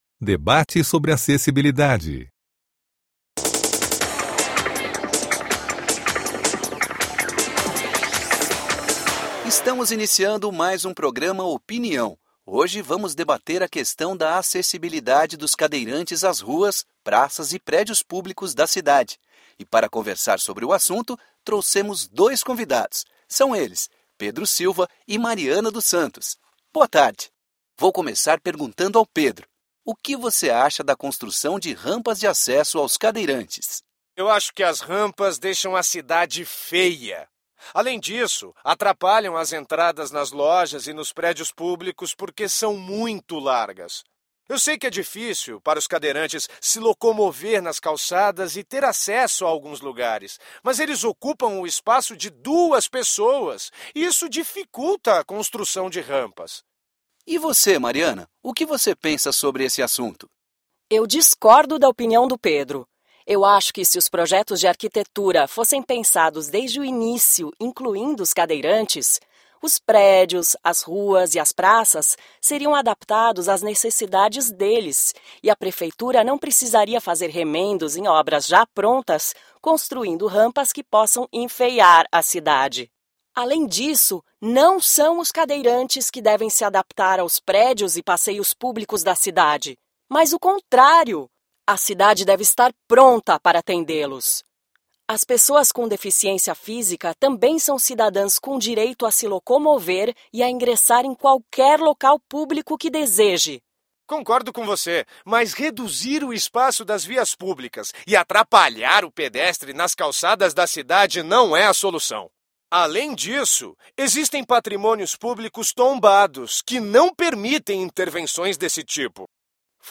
Debate sobre acessibilidade
BUpor3_un07_debate.mp3